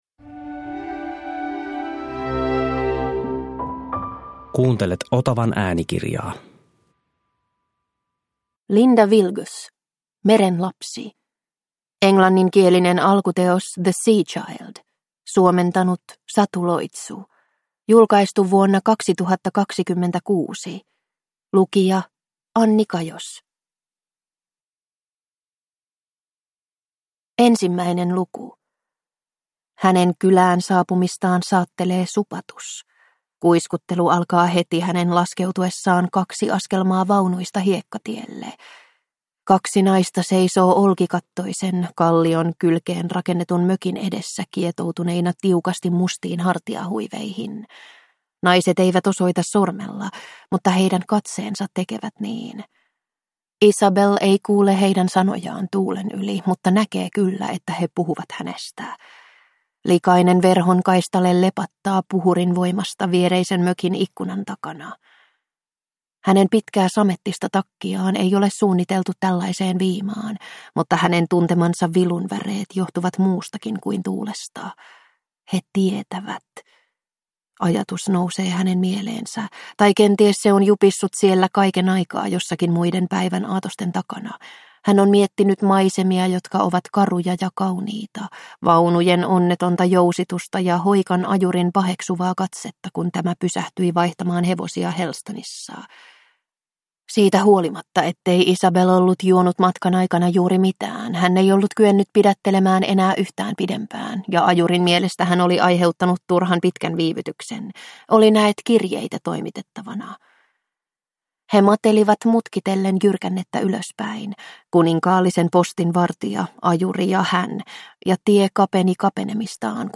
Meren lapsi – Ljudbok